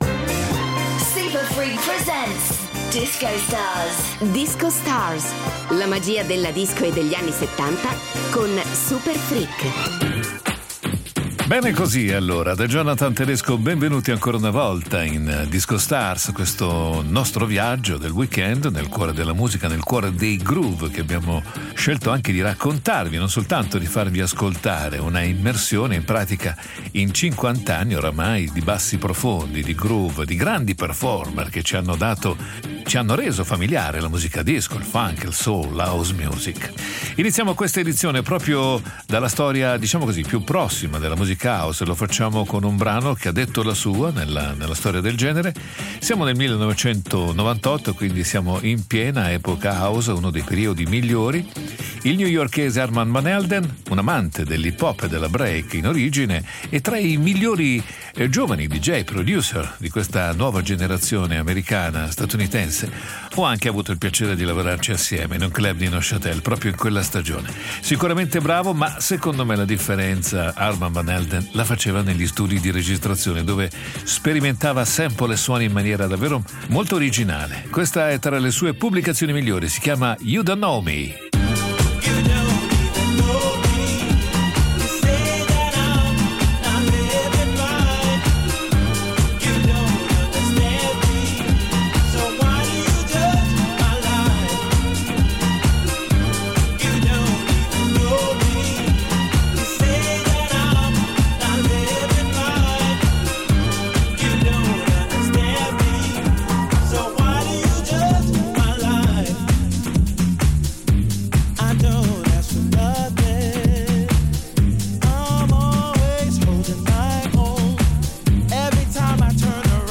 RAP / HIP-HOP BLACK